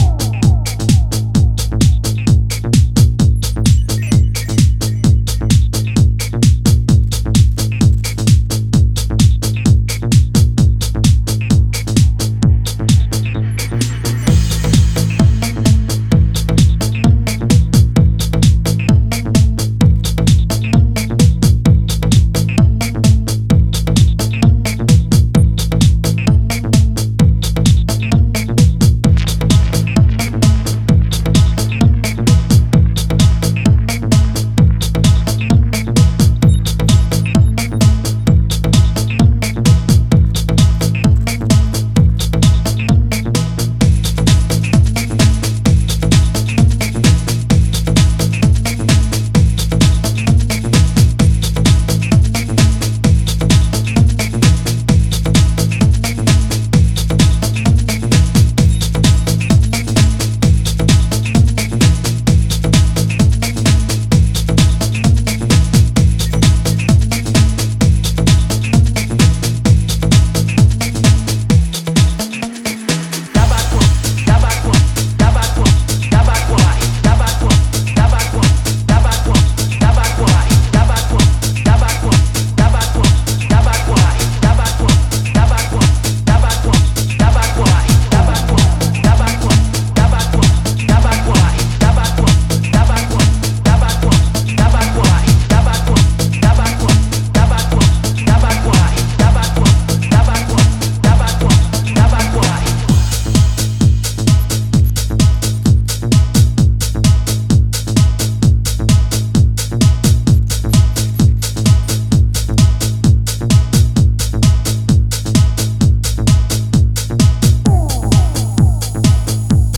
house tracks. All tunes were recorded in Montmartre, Paris